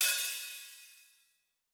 TC2 Live Hihat14.wav